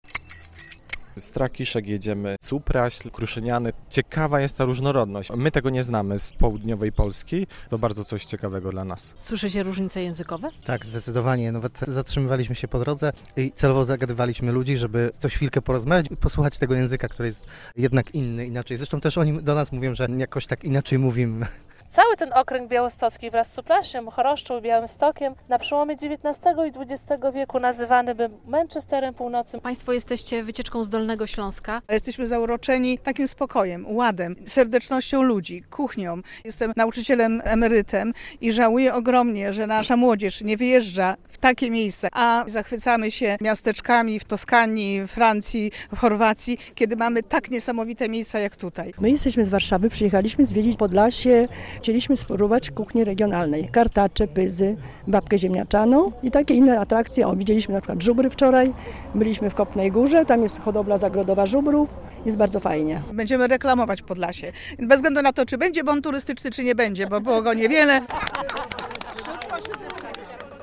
Majówka w Supraślu - relacja
Z naszego plenerowego studia ustawionego przed Pałacem Buchholtzów zachęcaliśmy do spacerowania po miasteczku i relaksu w okolicznych lasach.